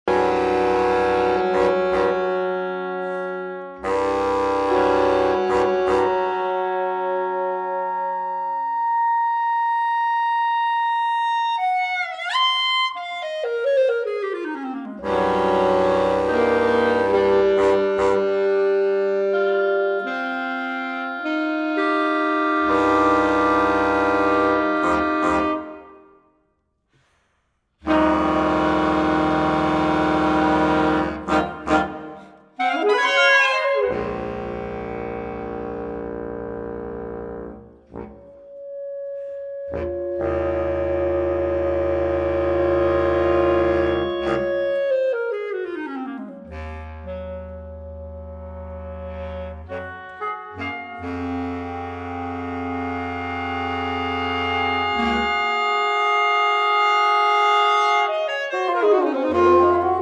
Reed Quintet